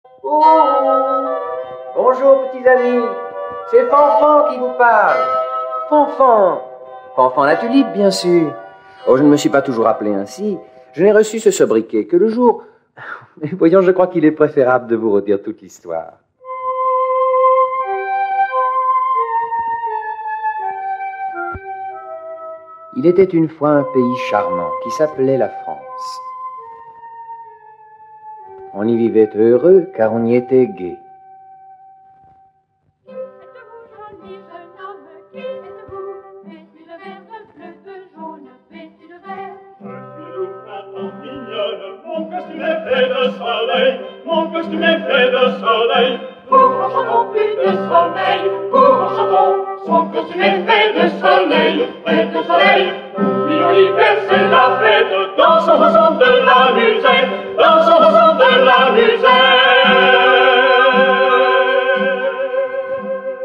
0% Extrait gratuit Fanfan la tulipe de René Fallet , René Wheeler Éditeur : Compagnie du Savoir Paru le : 2010 Intrépide, fougueux, effronté et généreux, Fanfan la Tulipe, soldat dans l'armée du roi, part à la conquête de la gloire et du coeur de la belle Adeline, la fille du sergent recruteur. Voici une adaptation audio parue en 1954 avec l'inoubliable Gérard Philipe, dans le rôle de Fanfan la Tulipe.